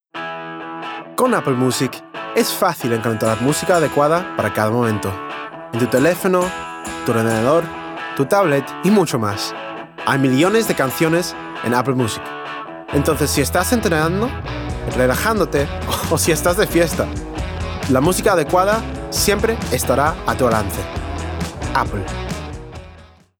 Conversational, Bright, Upbeat, Natural